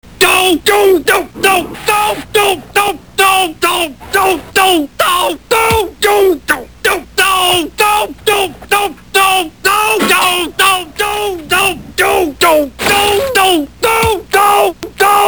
Homer's 32 Doh's-32 different doh's.